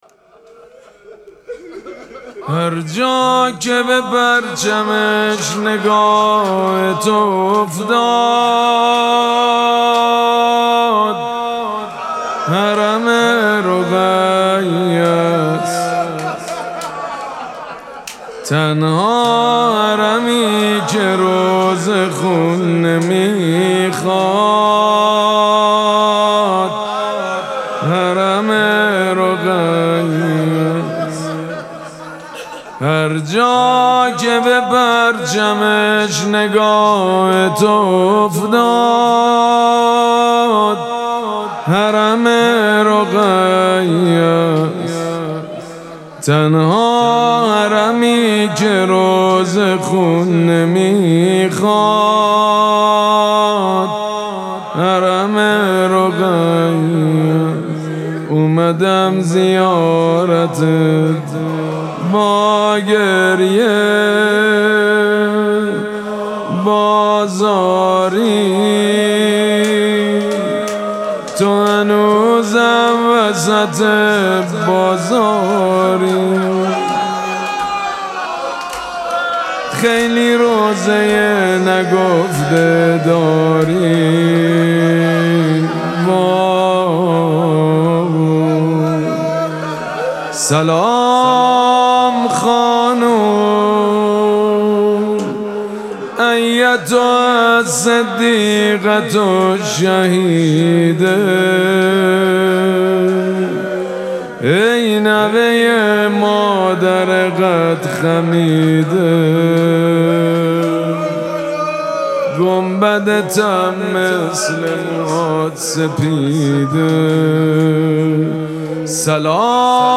مراسم مناجات شب هجدهم ماه مبارک رمضان
روضه
مداح